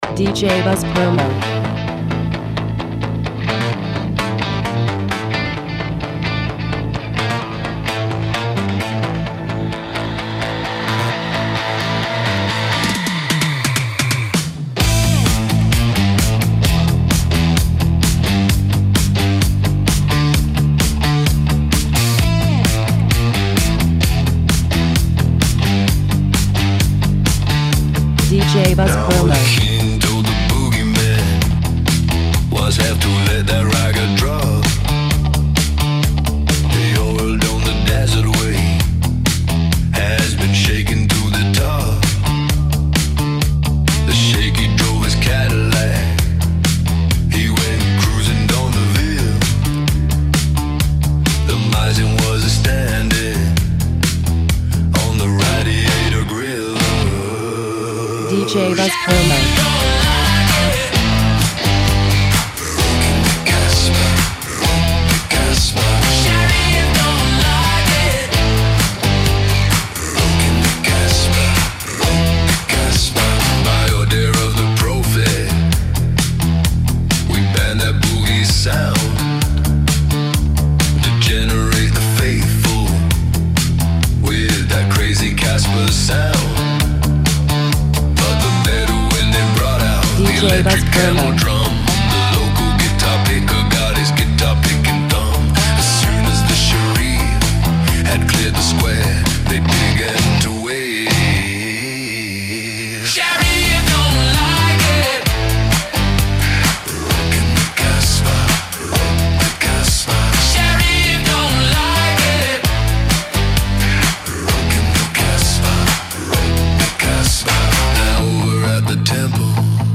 Original Mix
Rock Electro